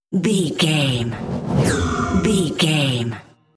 Airy whoosh pass by
Sound Effects
futuristic
pass by
sci fi